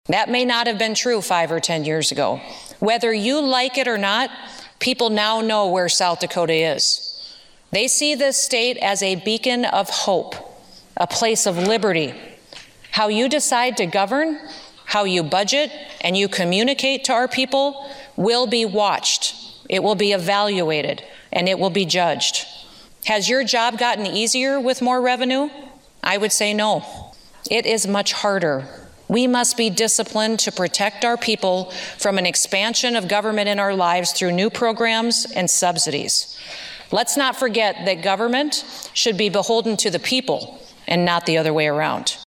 South Dakota Governor Kristi Noem delivered her 2022 Budget Address today (Dec. 6, 2022), outlining her spending plan for part of Fiscal Year 2023 and all of Fiscal Year 2024.